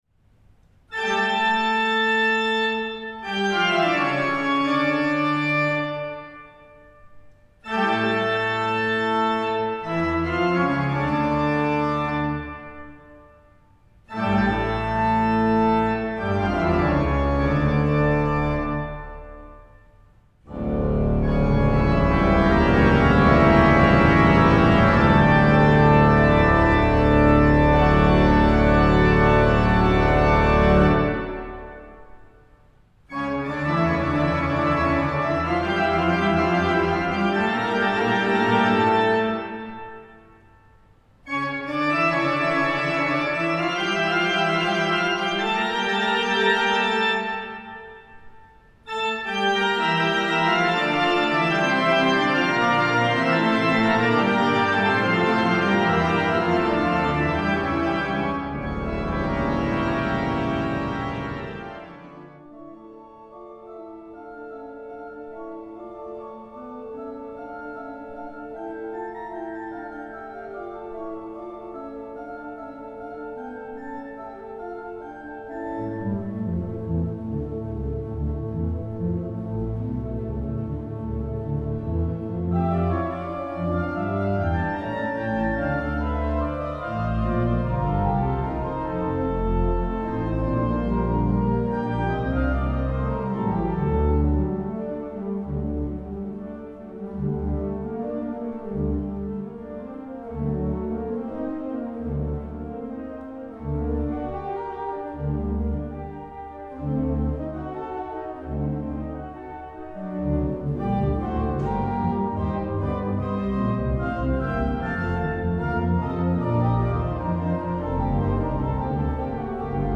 Konzert CD
WALCKER-Orgel von 1928.
Zugabe